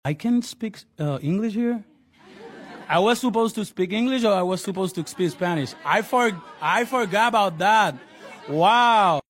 Ever been so excited you forgot what language you're supposed to speak? Bad Bunny has a hilarious moment of confusion during his Tiny Desk Concert!